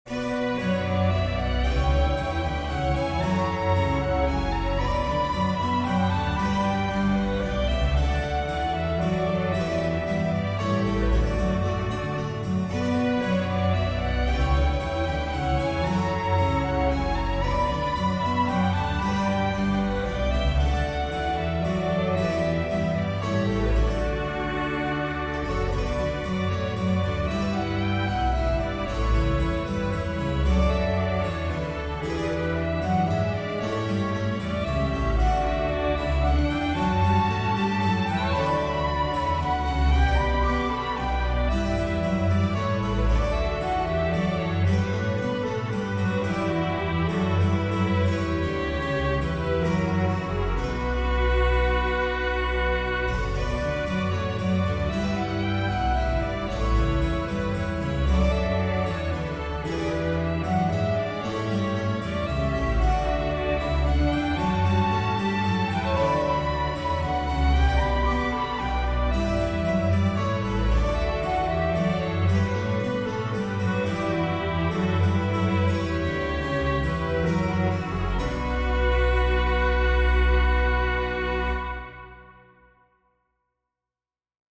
Minuet For Baroque Orchestra
Really easy to listen to. Here in the UK it would be classed as "light music" and is entirely successful at that - probably playable at sight for performers in that arena.